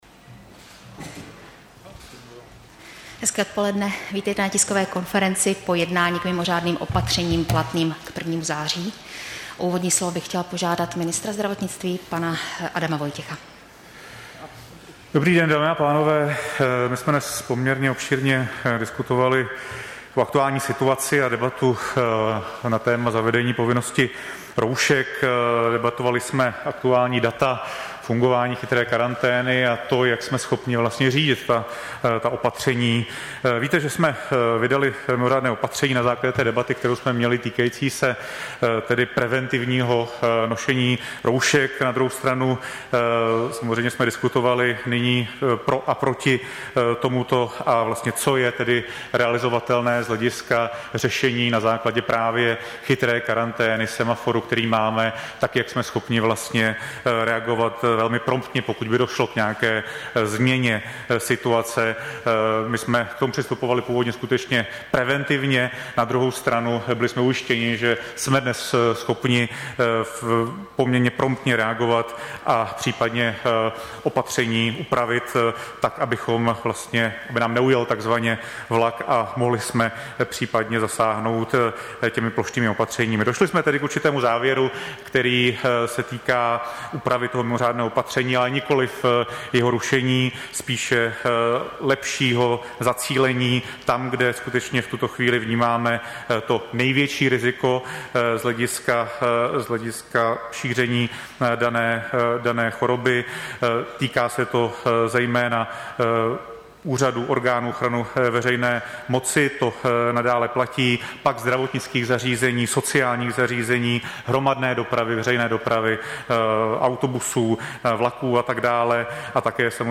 Tisková konference ministra zdravotnictví k mimořádným opatřením, 20. srpna 2020